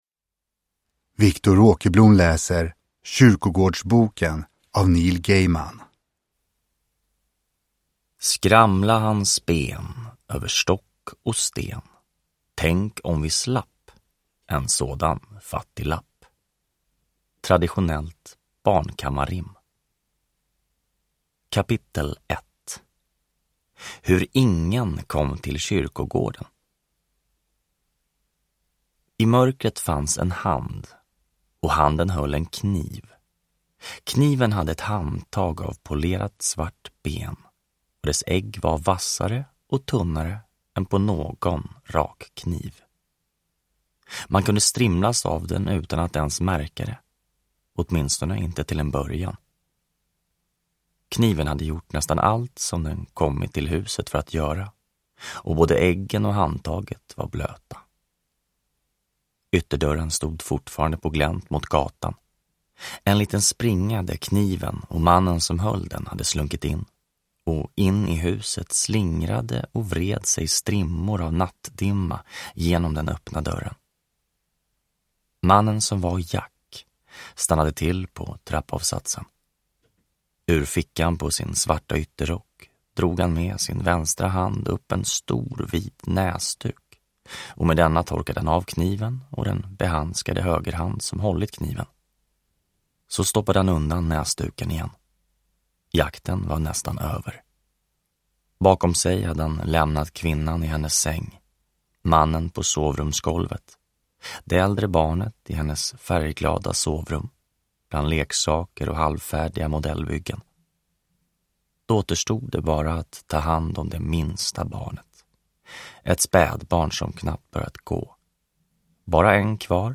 Kyrkogårdsboken – Ljudbok – Laddas ner